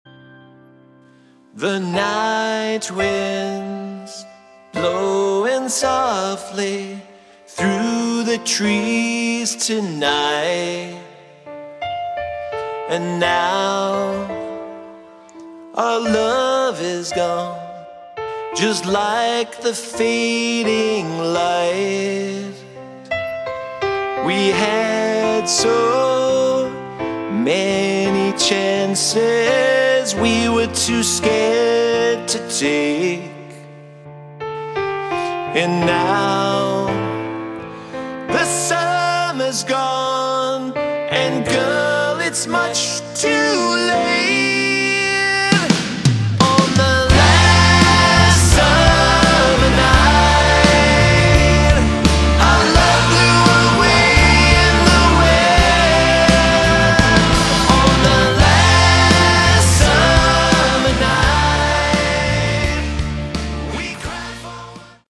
Category: Melodic Rock
vocals
bass
guitars
keyboards
drums